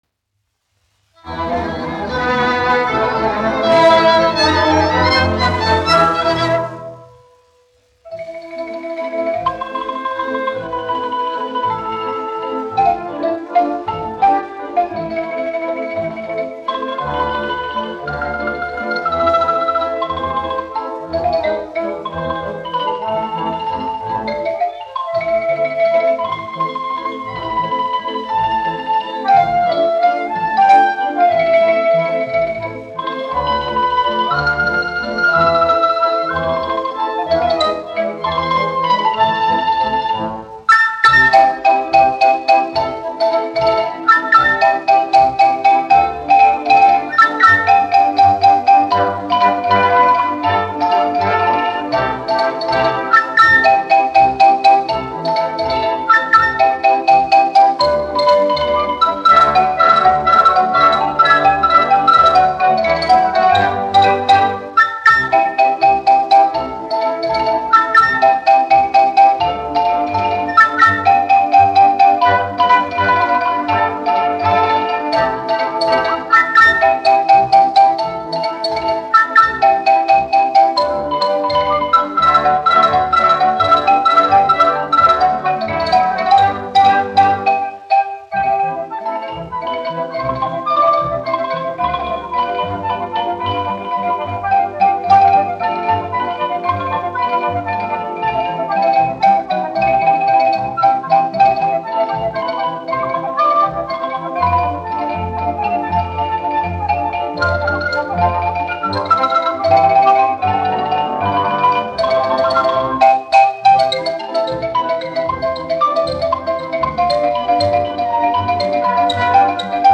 1 skpl. : analogs, 78 apgr/min, mono ; 25 cm
Valši
Ksilofons ar orķestri
Skaņuplate